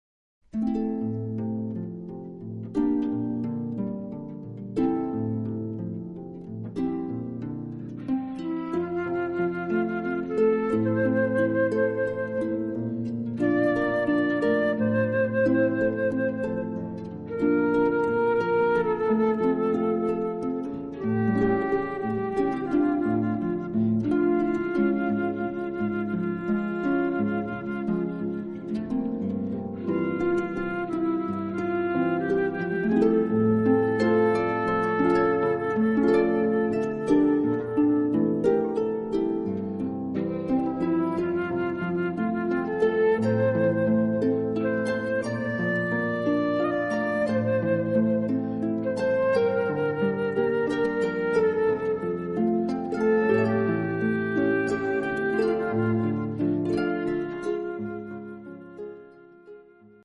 Arpa e Flauto
Musiche moderne d'accompagnamento alle firme